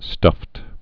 (stŭft)